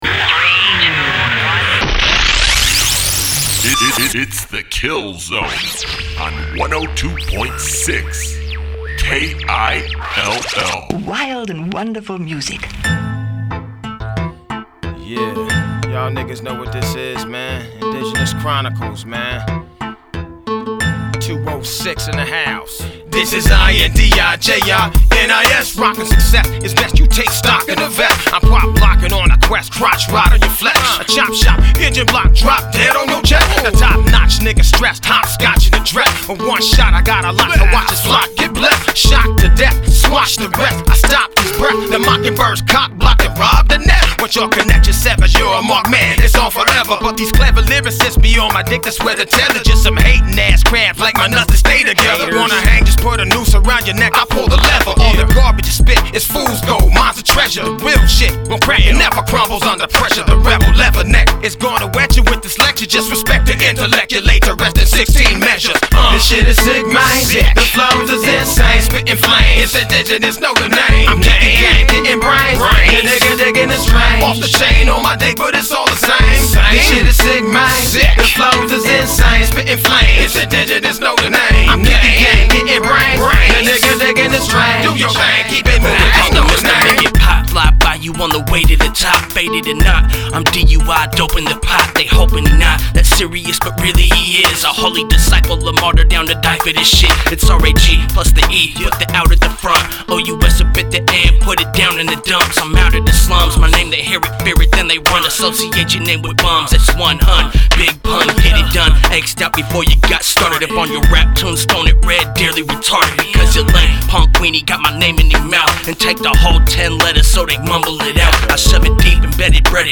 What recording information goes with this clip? Recorded at Ground Zero Studios